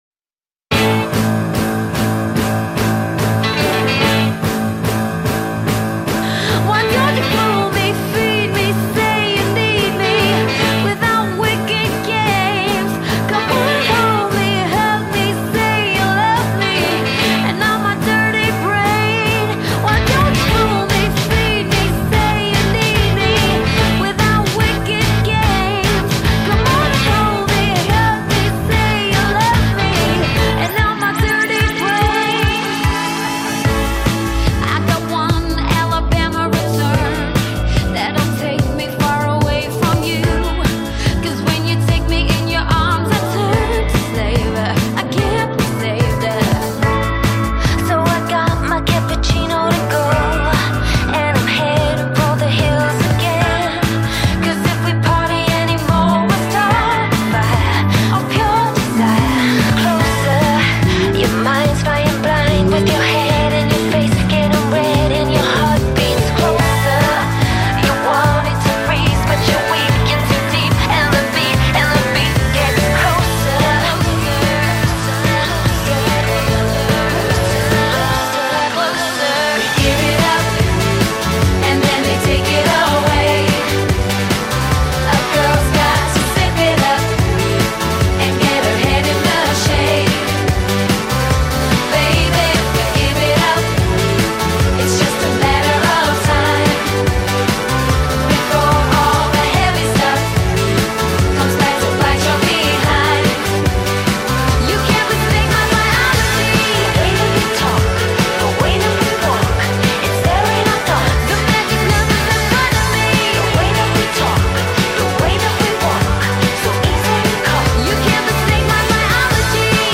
Audio QualityPerfect (High Quality)
146 bpm